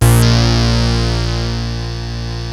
OSCAR A#2 2.wav